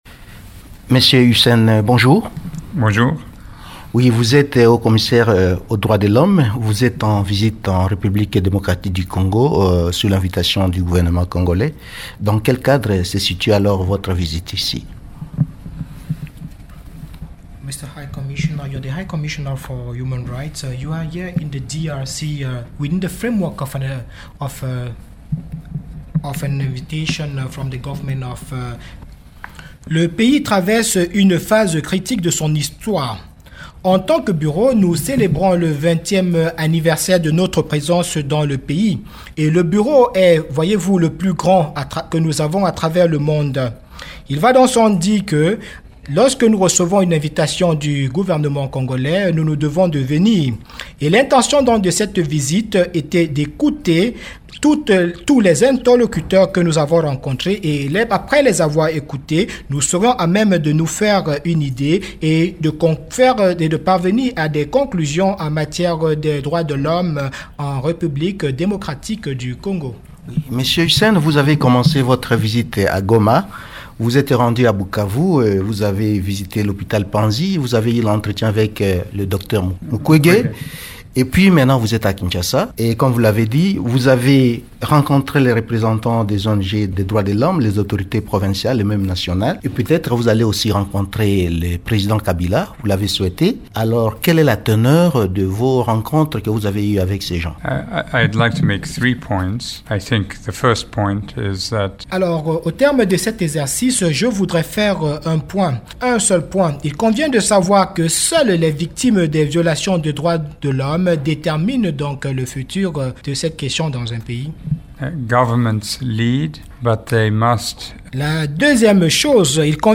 Invité de Radio Okapi ce vendredi, Zeid Ra’ad Al Hussein a expliqué être en visite en RDC sur invitation du gouvernement congolais. Sa démarche en effectuant notamment une  tournée à l’Est de la RDC était d’écouter tous les interlocuteurs qui interviennent dans le secteur des droits de l’homme au pays afin de se faire une idée et tirer les conclusions sur la situation des droits de l’homme en RDC, a-t-il expliqué.